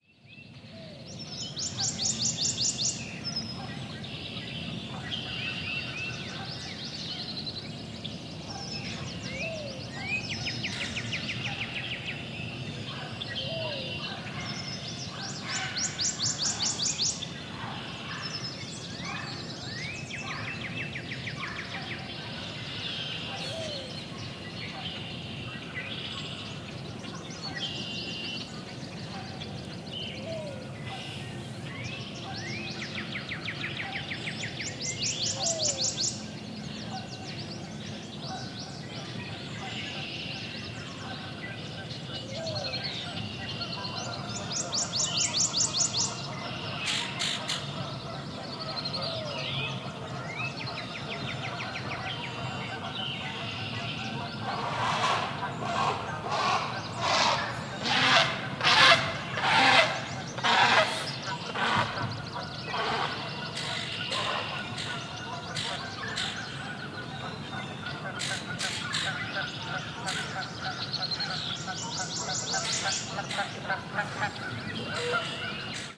Prothonotary Warbler and friends (listen for the Great Blue Heron solo at :55) – Recorded April 29, 2012 from the East Observation Tower of Lord Stirling Park, Somerset County, NJ.
protho-raw-with-normalizing-with-high-pass-filter-24dbrolloff.mp3